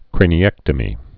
(krānē-ĕktə-mē)